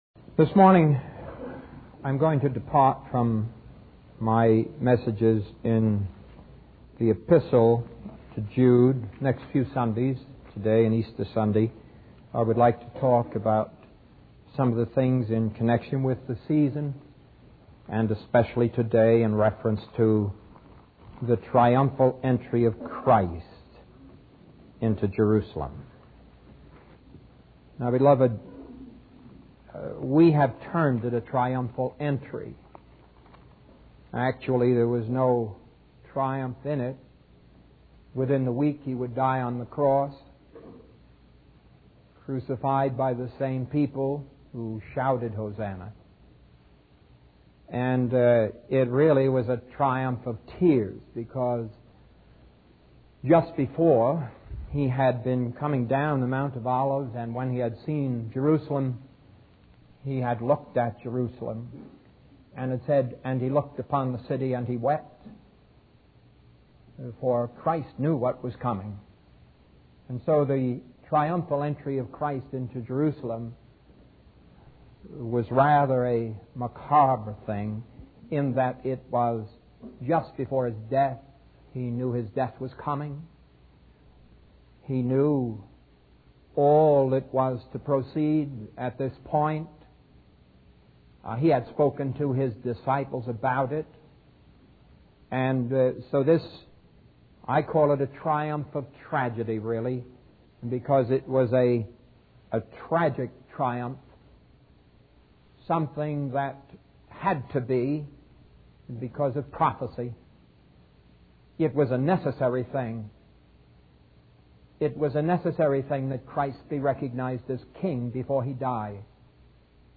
In this sermon, the preacher emphasizes the importance of going out and spreading the word of God. He compares the act of evangelizing to untying and loosing people from their sins and bringing them to Jesus.